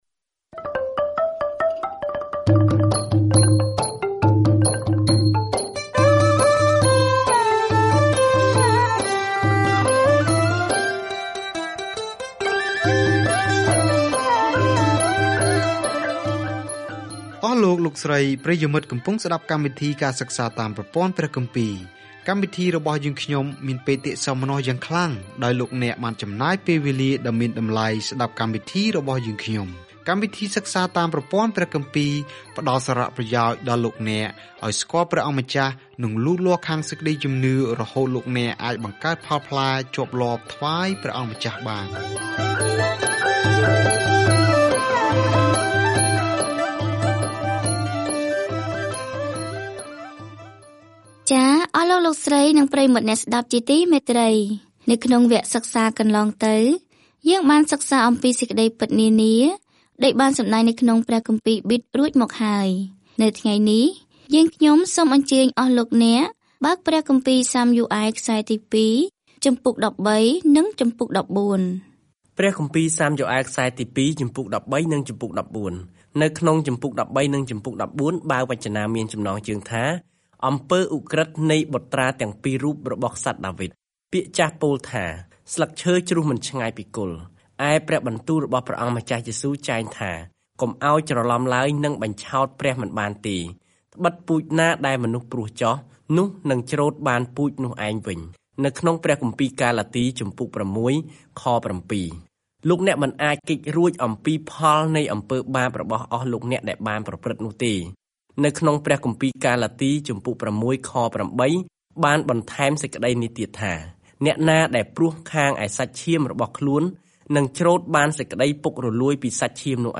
រឿងនៃទំនាក់ទំនងរបស់អ៊ីស្រាអែលជាមួយព្រះបន្តជាមួយនឹងការណែនាំរបស់ព្យាការីក្នុងបញ្ជីនៃរបៀបដែលព្រះភ្ជាប់ជាមួយនឹងរាស្ដ្ររបស់ទ្រង់។ ការធ្វើដំណើរប្រចាំថ្ងៃតាមរយៈ 2 សាំយូអែល នៅពេលអ្នកស្តាប់ការសិក្សាជាសំឡេង ហើយអានខគម្ពីរដែលជ្រើសរើសចេញពីព្រះបន្ទូលរបស់ព្រះ។